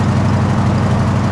Diesel truck engine idle
A brand new diesel engine in perfect sounding condition.
s_diesel_traktor_edd22s.wav